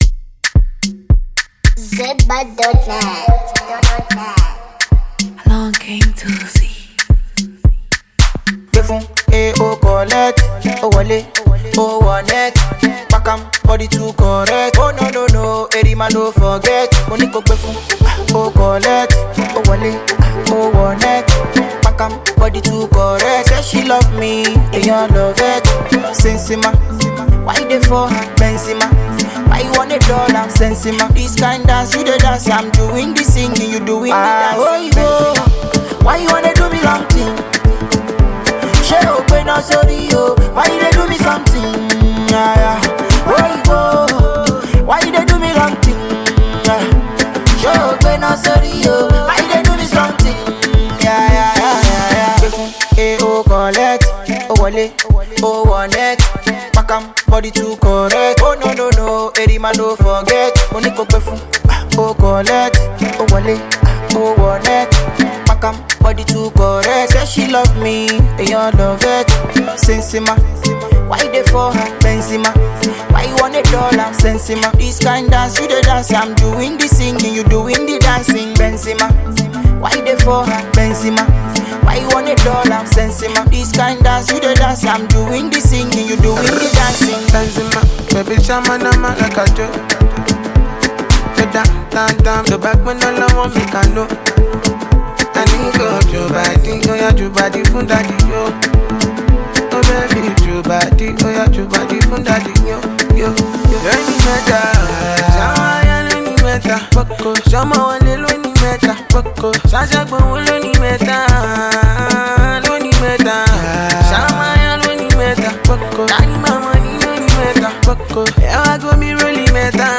It's perfect for parties or just dancing around your room.